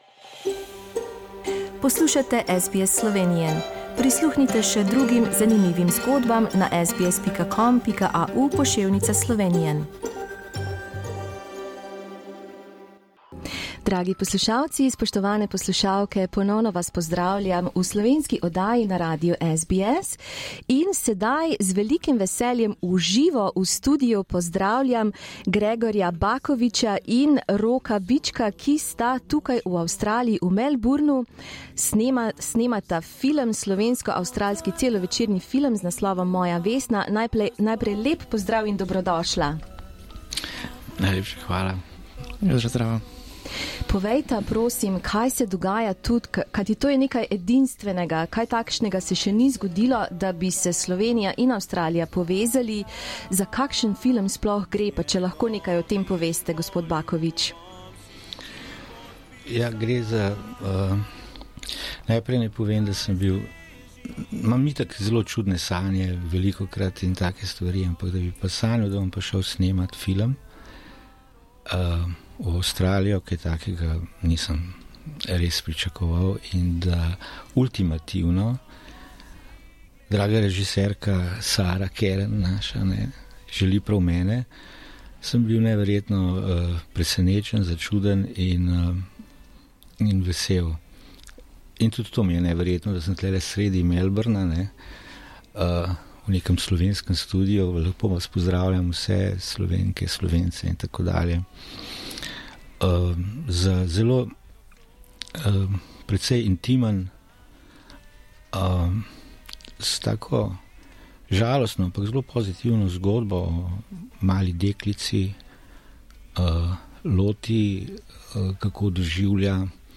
V pogovoru boste izvedeli, da vse ni tako zelo rožnato, kot se sliši. Film Moja Vesna bo naslednje leto predstavljen na Melbourne Film Festival.